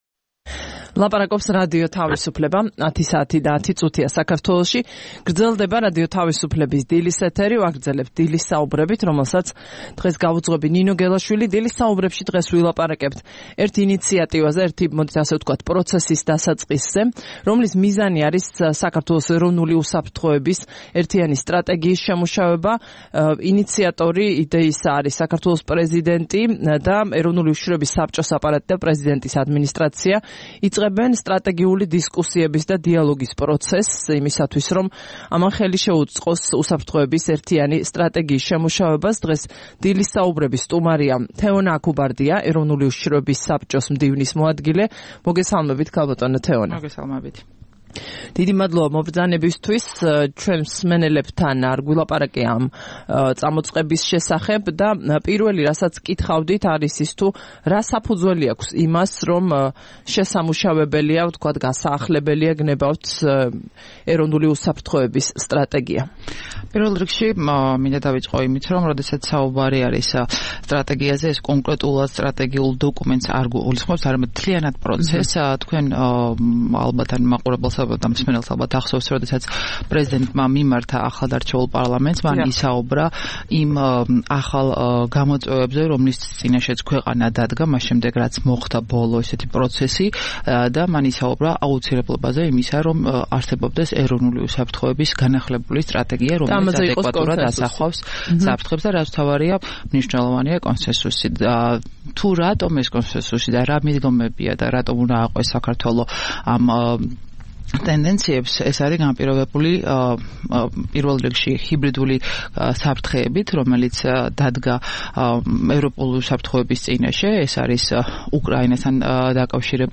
15 თებერვალს რადიო თავისუფლების "დილის საუბრების" სტუმარი იყო თეონა აქუბარდია, ეროვნული უშიშროების საბჭოს მდივნის მოადგილე.